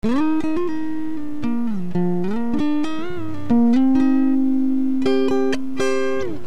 Original Unprocessed Signal